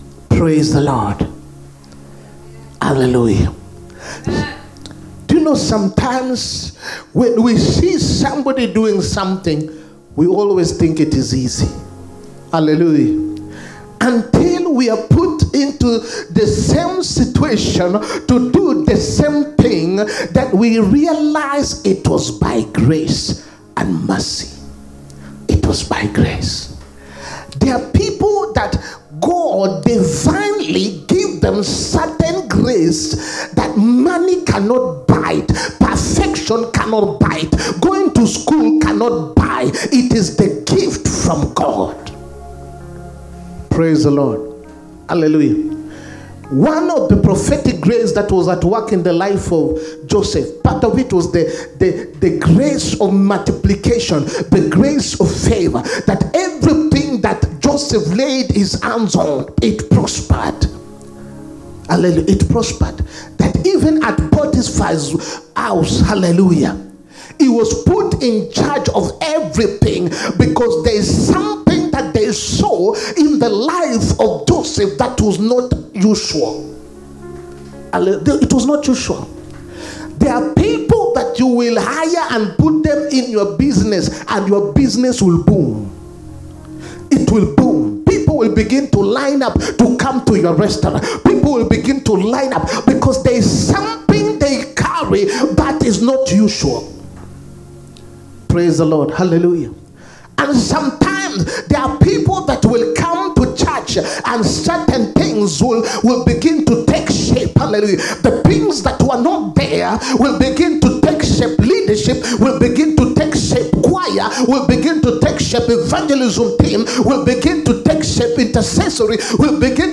SUNDAY HOLY COMMUNION SERVICE. IMPORTANCE OF HOLY COMMUNION. 25TH MAY 2025.